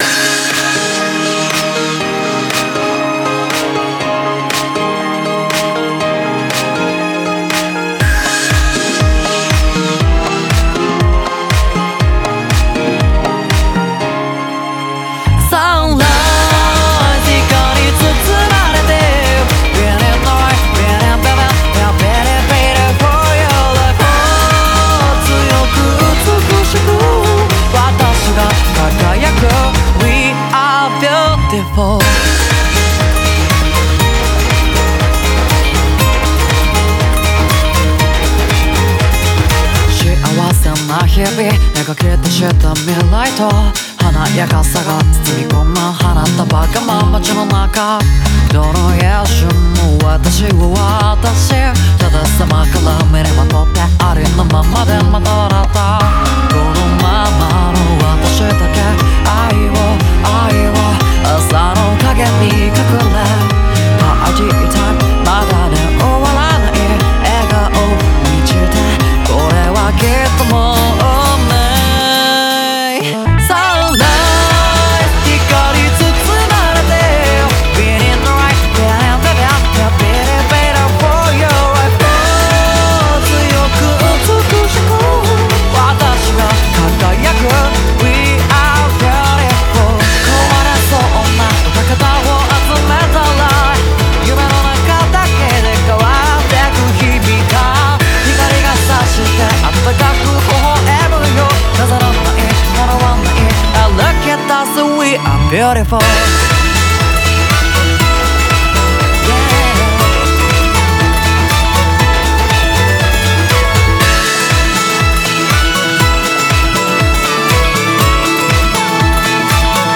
BPM120
MP3 QualityMusic Cut